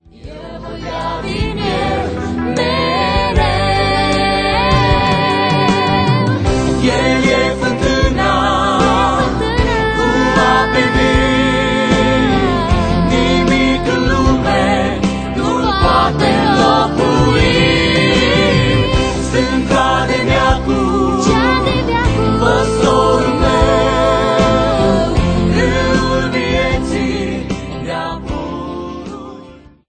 Personalitate, forta, dinamism, energie si originalitate.